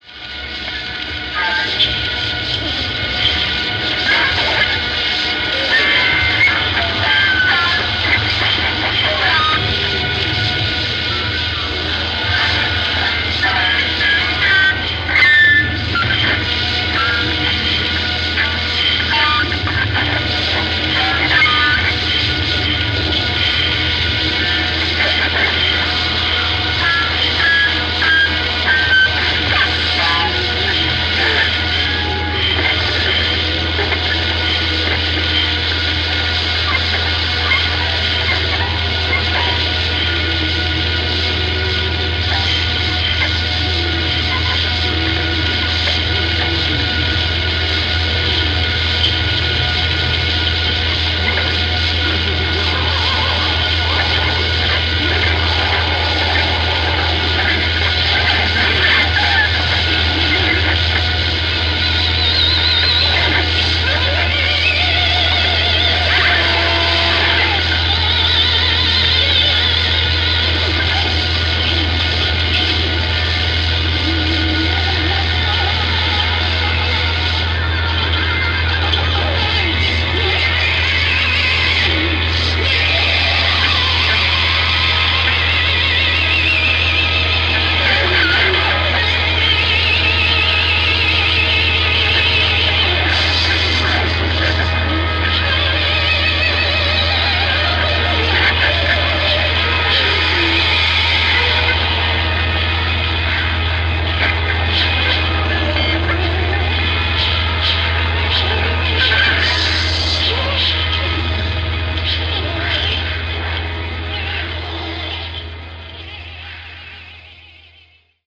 Melting cinematic sounds.